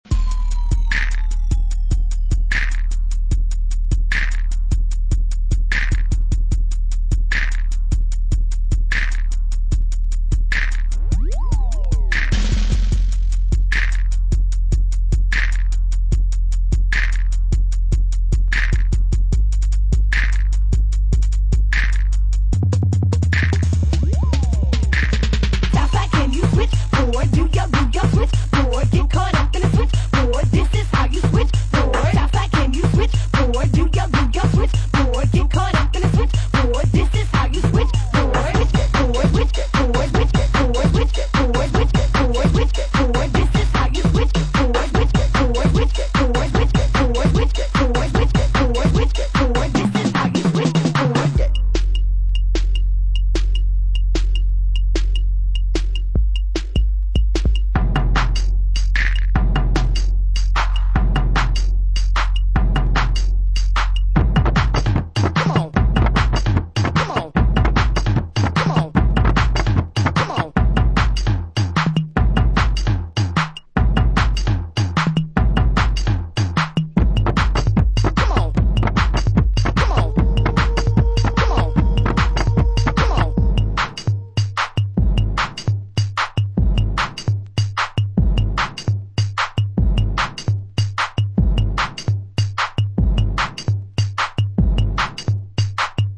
Some bangin electro booty tracks